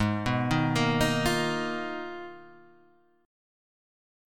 G#m6add9 chord